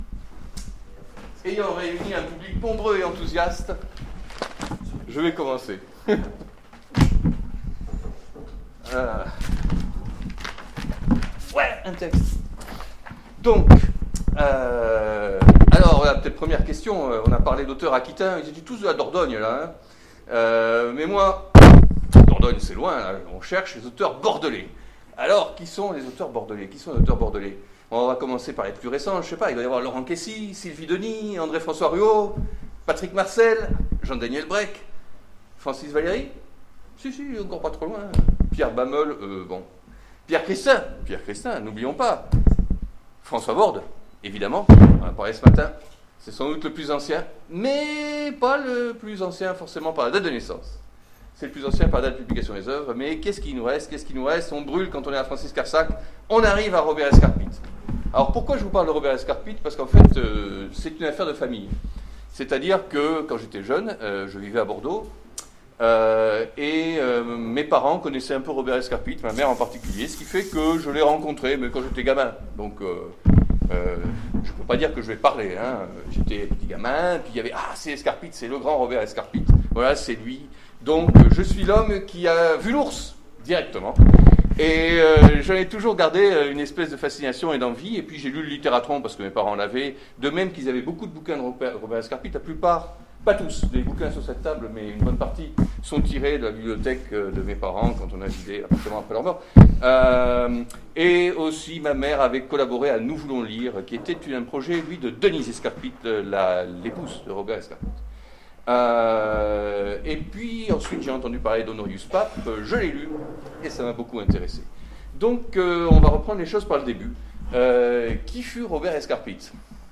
Convention SF 2016 : Conférence Robert Escarpit